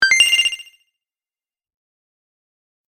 sons de trrring